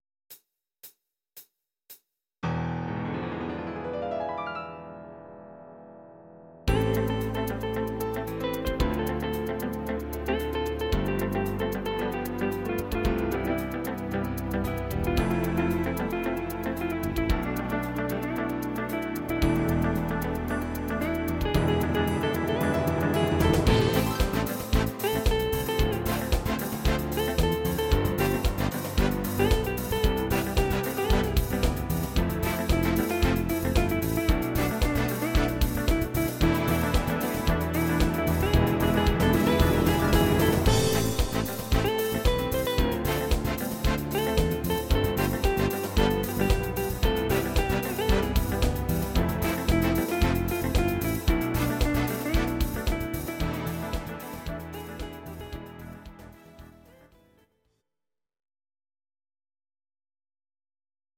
Please note: no vocals and no karaoke included.
Your-Mix: Disco (724)